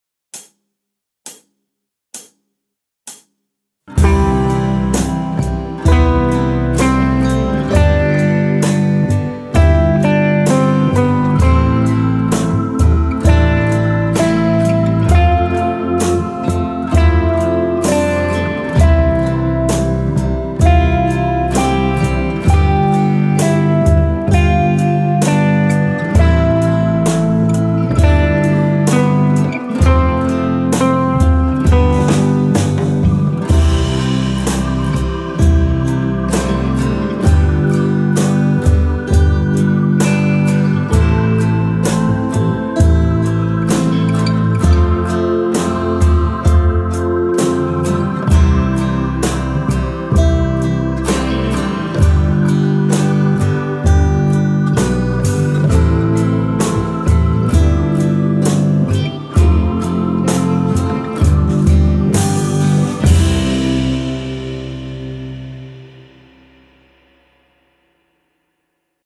Slow C instr (demo)